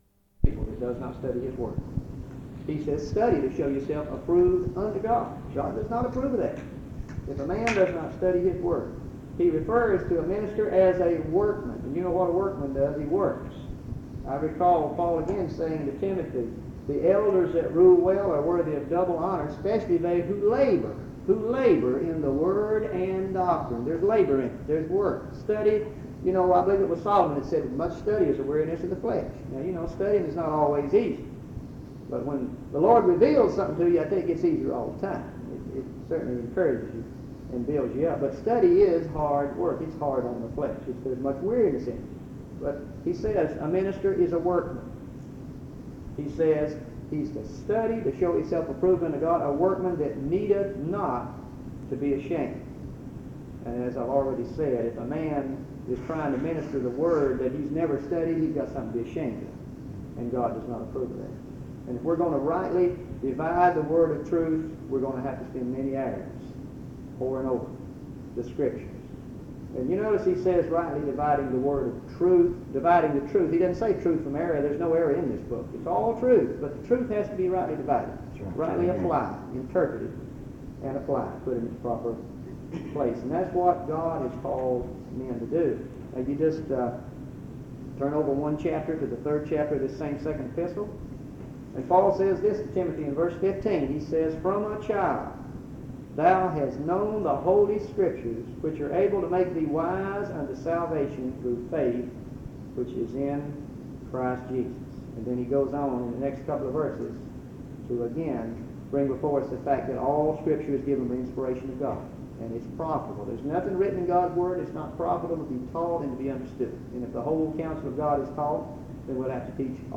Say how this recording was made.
Walnut Cove (N.C.)